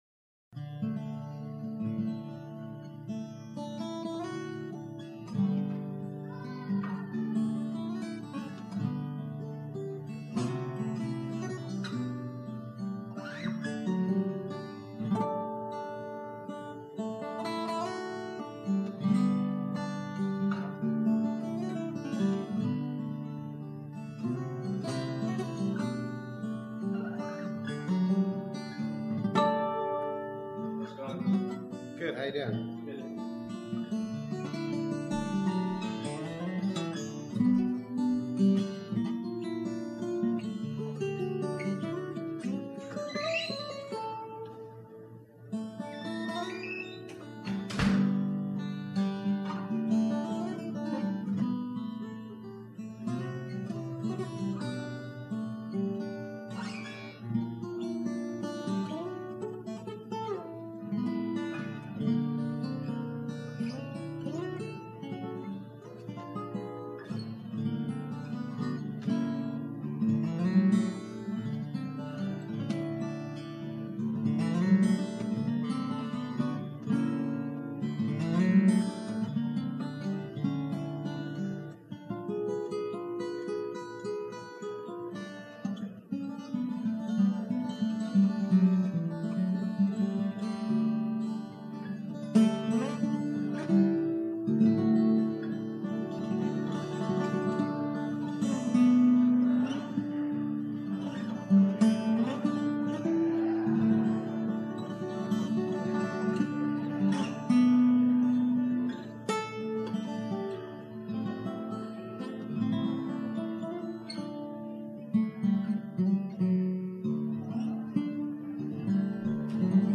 Series: 2005 Calvary Chapel Worship Leader Conference
Track: Acoustic Guitar Track, Musician Track
Campus: Calvary Chapel Costa Mesa